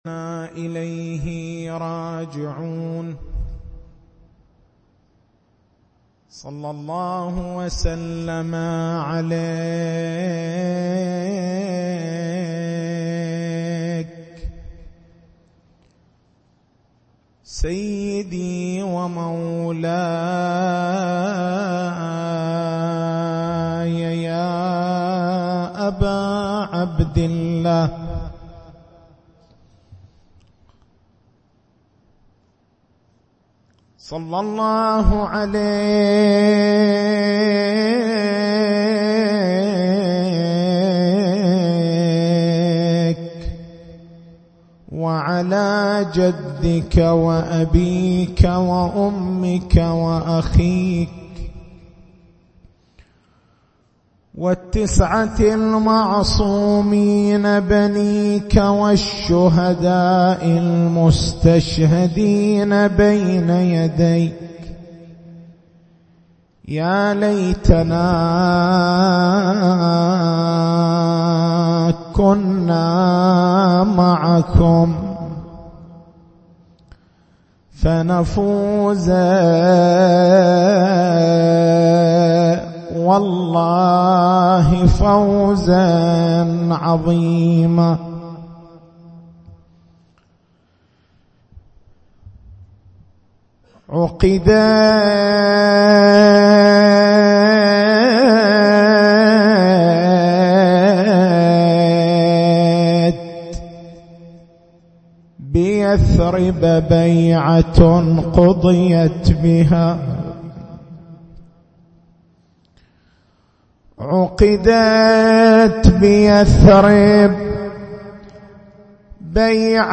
تاريخ المحاضرة: 10/09/1438 نقاط البحث: ما معنى سلام الله تعالى على خديجة (ع)؟ ما هو وجه حاجة جبرئيل (ع) إلى السلام على خديجة (ع)؟